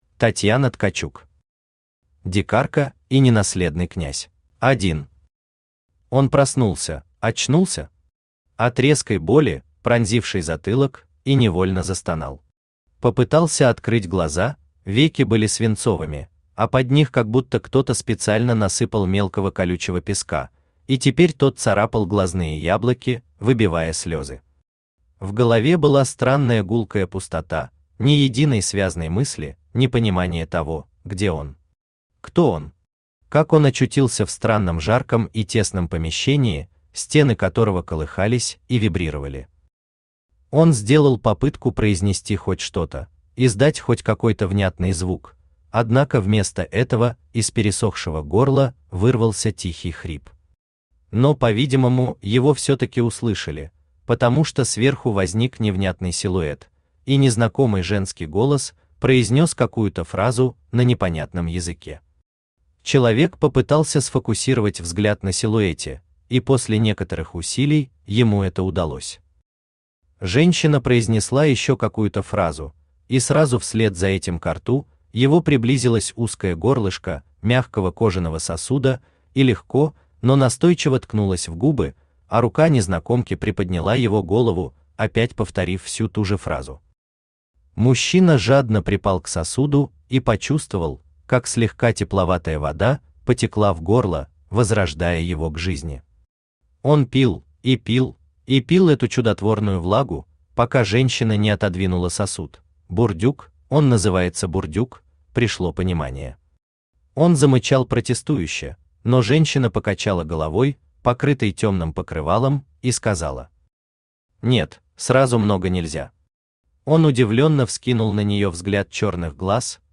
Аудиокнига Дикарка и ненаследный князь | Библиотека аудиокниг
Aудиокнига Дикарка и ненаследный князь Автор Татьяна Ткачук Читает аудиокнигу Авточтец ЛитРес.